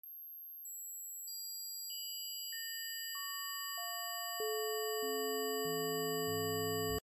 🌀 It’s the 432 Hz Golden Ratio Frequency — a sound field designed to bring your entire system back into balance. 432 Hz is a natural, grounding tone known to calm the nervous system, slow your heart rate, and quiet the mind. But the real magic is in how it’s built: I layer 13 harmonics, each spaced using the golden ratio — 1.618 — the same spiral found in galaxies, flowers, seashells, DNA, and even the rhythm of your heartbeat.